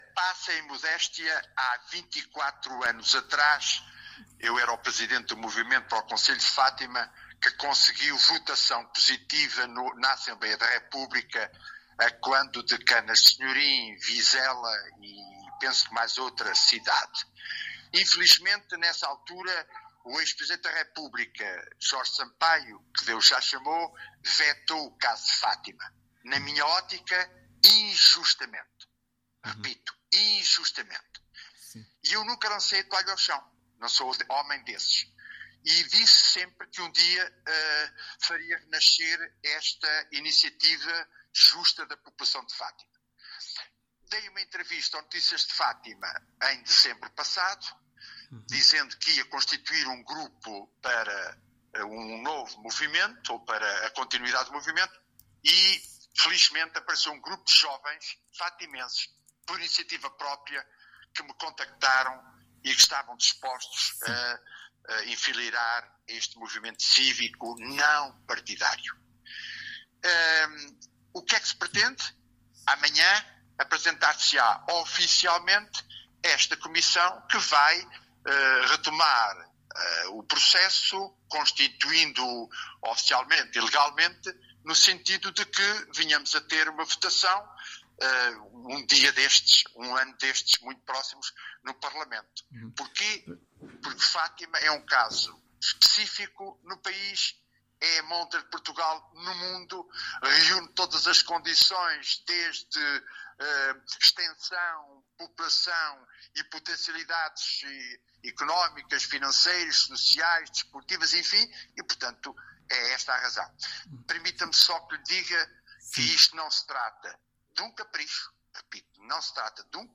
ÁUDIO | VITOR FRAZÃO, MOVIMENTO FÁTIMA A CONCELHO: